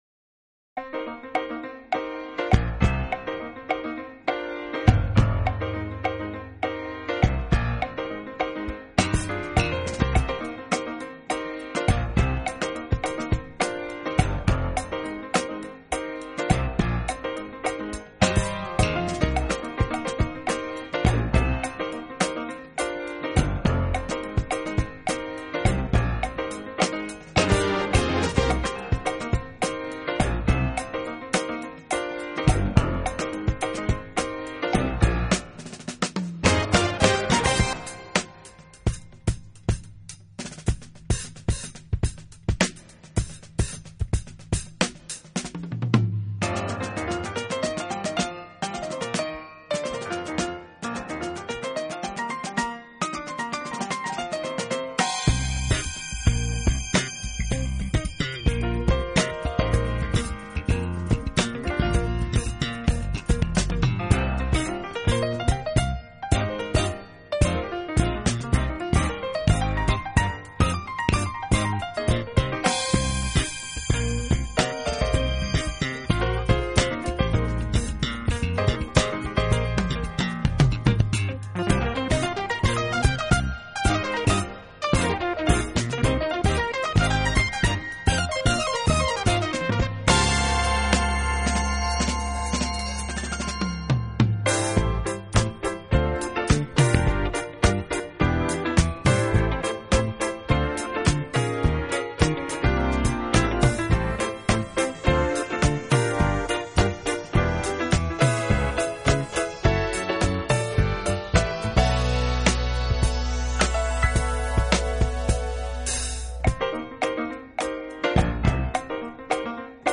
专辑类型：JAZZ
曲风稍偏重于轻快柔 和，并充分把握了爵士乐各种不同的风貌：时而华丽，时而细腻，或雀跃，或慵懒。